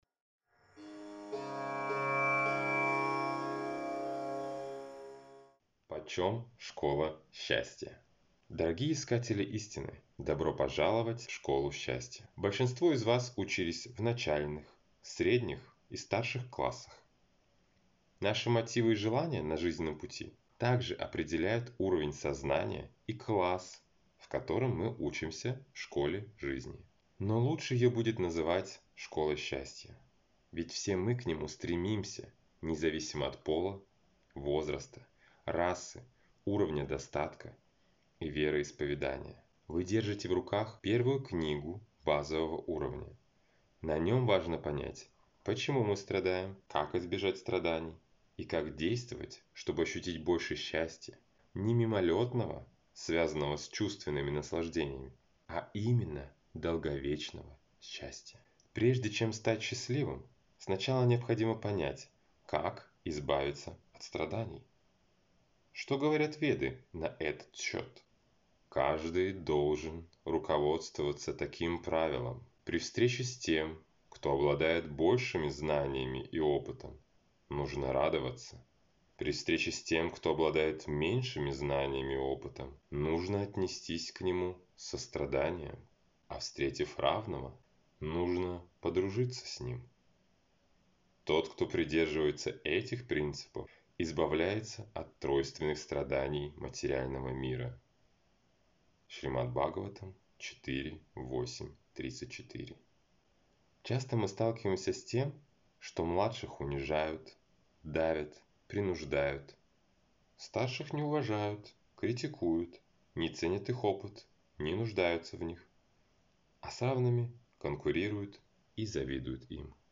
Аудиокнига Вакцина счастья. Базовый уровень. Часть 1 | Библиотека аудиокниг